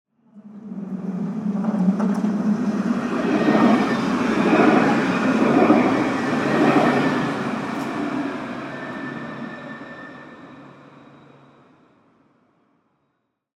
A modern Parisian tram passes by.
Listen : Tram passing #5 (14 s)